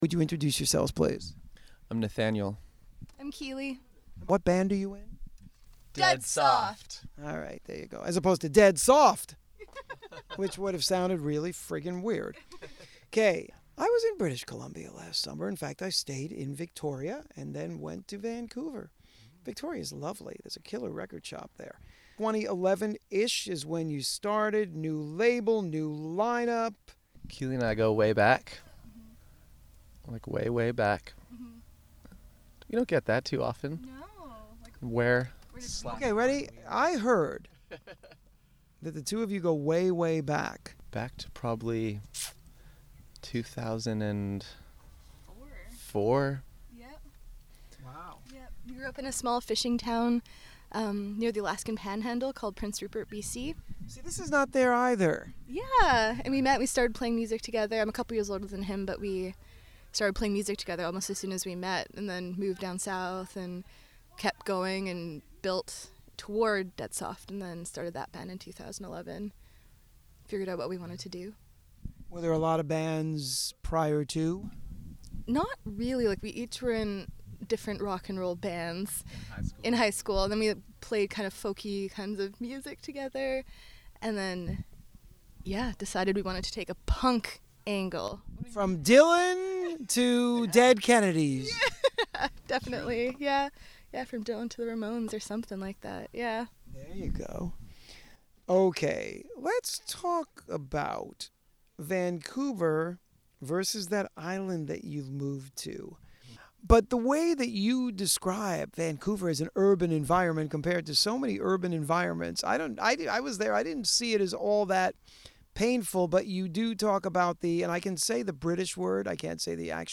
Dead Soft LISTEN TO THE INTERVIEW